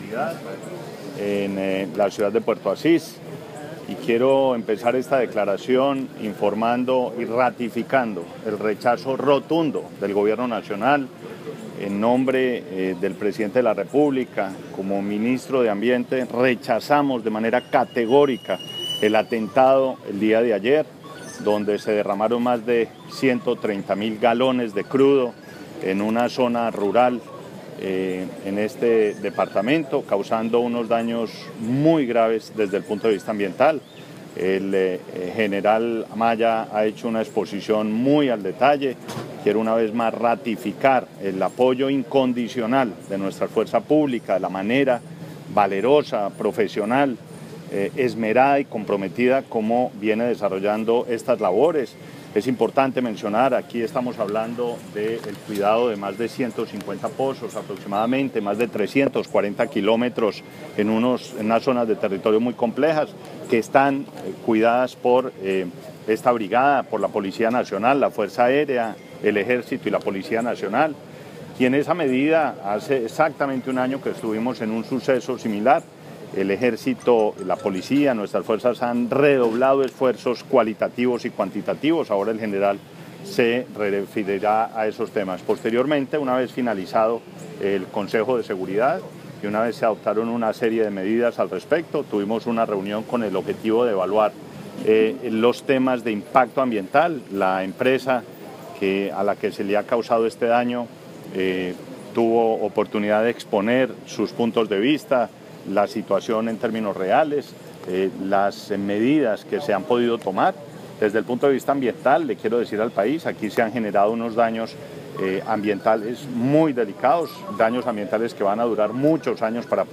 Declaraciones del Ministro de Ambiente y Desarrollo Sostenible, Gabriel Vallejo López
10-Puerto_Asis_Ministro.mp3